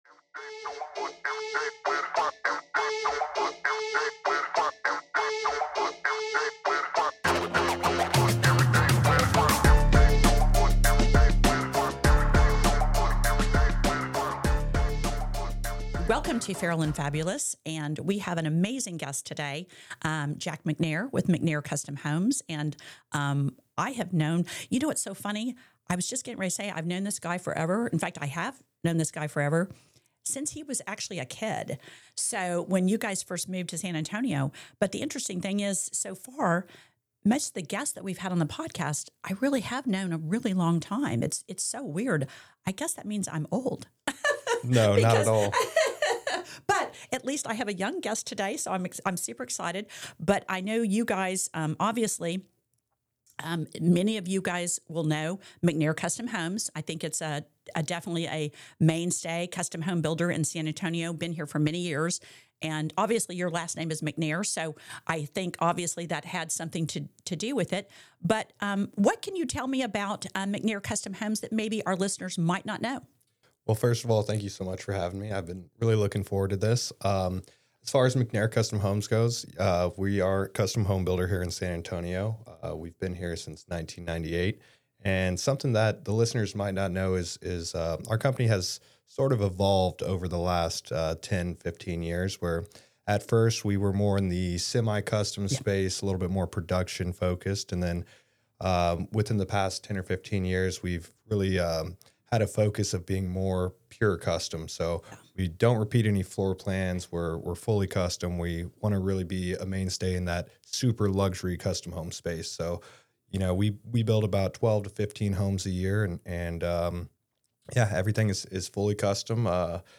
Cheers and Beginnings Each episode kicks off with a special toast, and this one is no different.